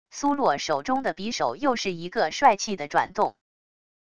苏落手中的匕首又是一个帅气的转动wav音频生成系统WAV Audio Player